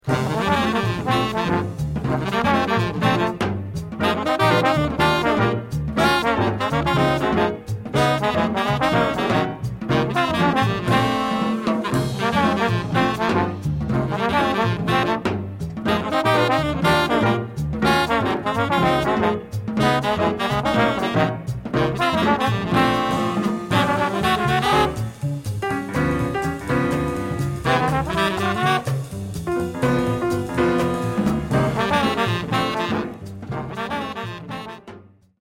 A tenor saxophonist of amazing energy and invention
Latin (medium up)